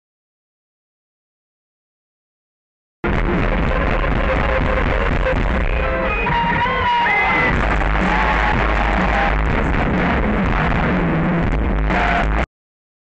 Kilby Block Party